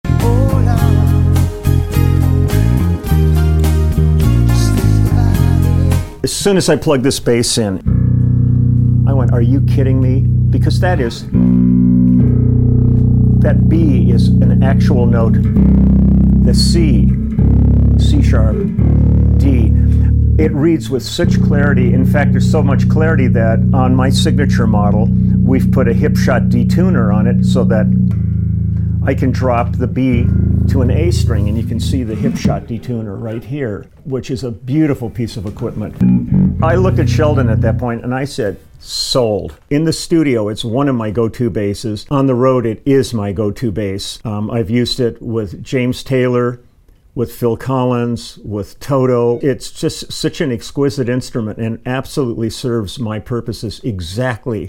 Bass legend Leland Sklar on his signature Dingwall 🔥!